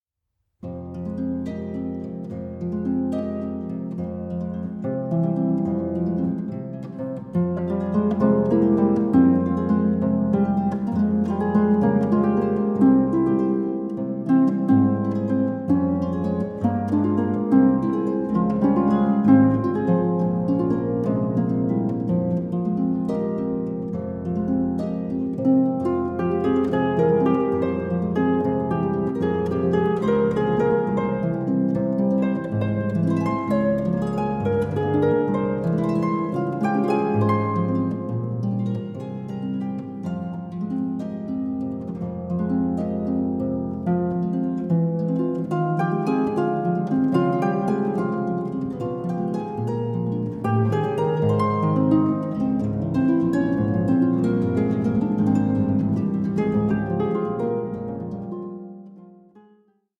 for harp